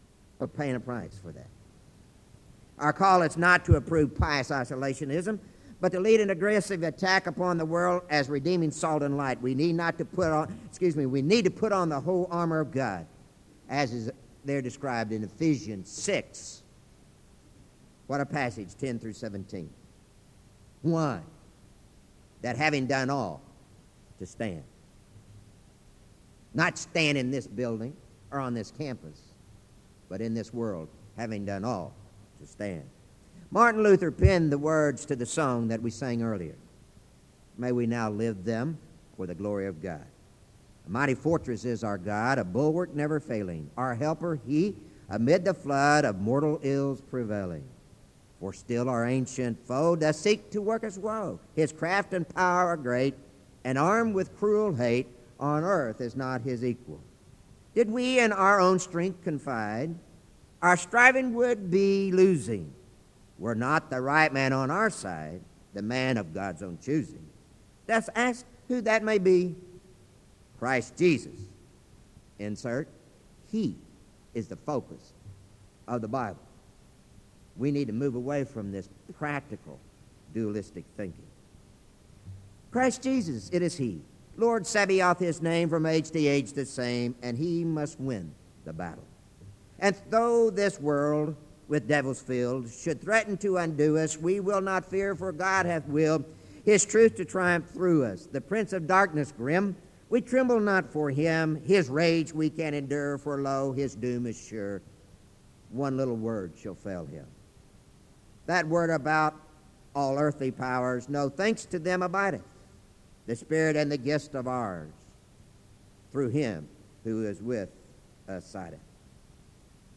In Collection: SEBTS Chapel and Special Event Recordings